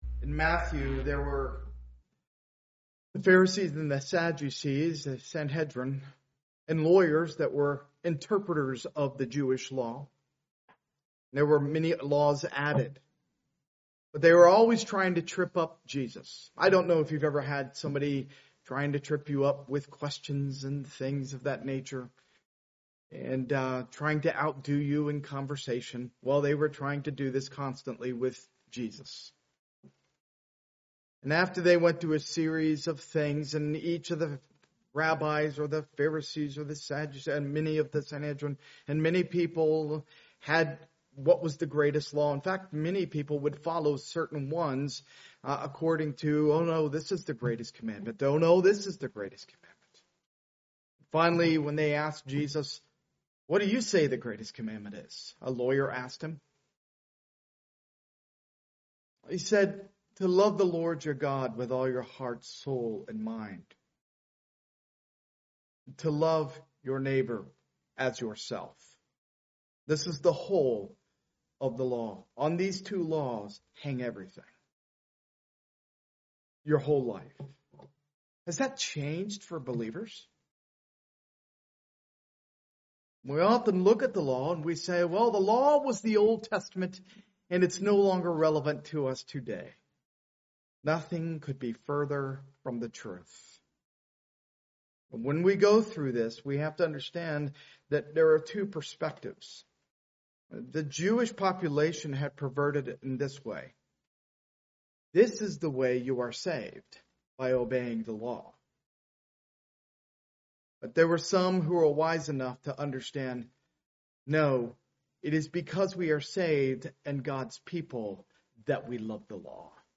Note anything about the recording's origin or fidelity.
Part of the The Book of Exodus series, preached at a Morning Service service.